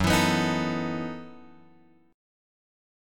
F# Minor 13th